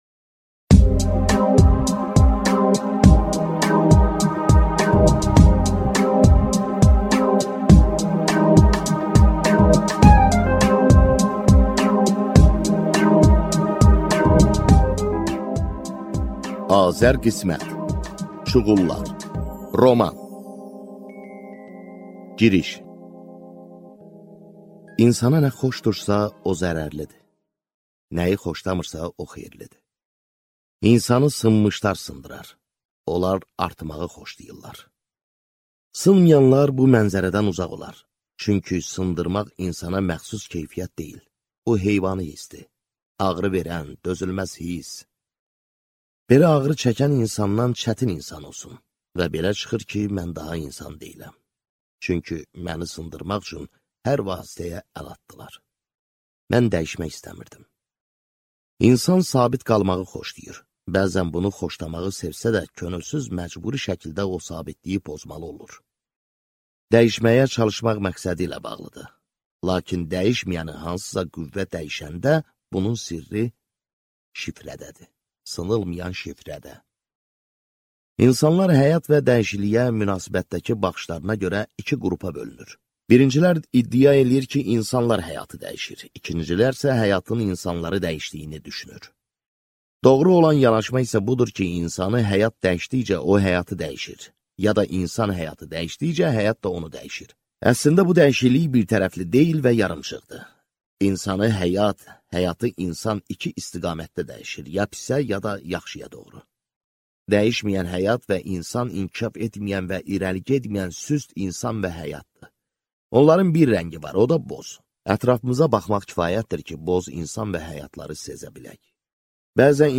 Аудиокнига Çuğullar | Библиотека аудиокниг
Прослушать и бесплатно скачать фрагмент аудиокниги